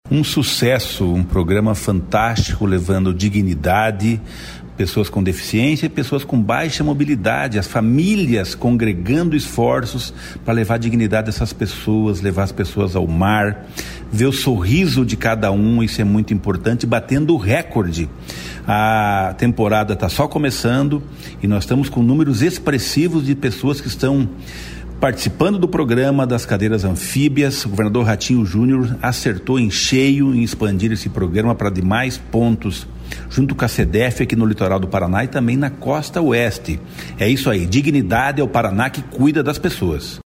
Sonora do secretário Estadual do Desenvolvimento Social e Família, Rogério Carboni, sobre o recorde do uso das cadeiras anfíbias na temporada de verão